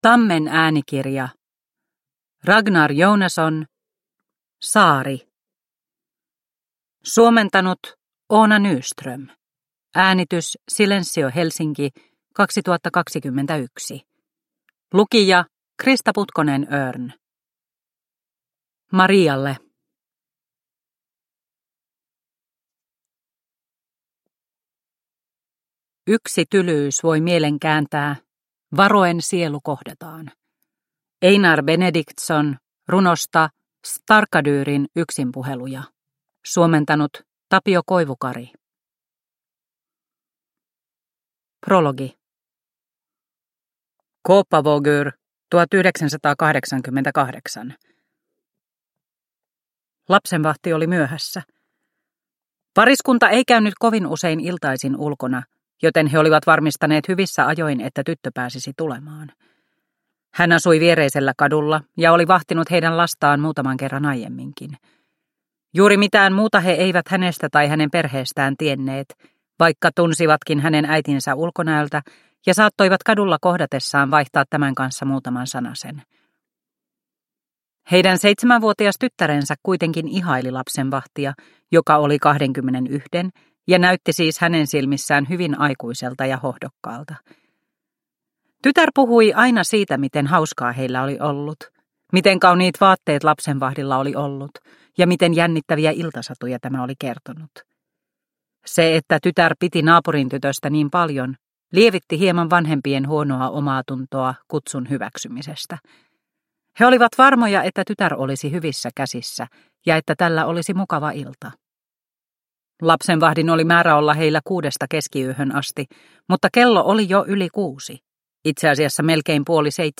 Saari – Ljudbok – Laddas ner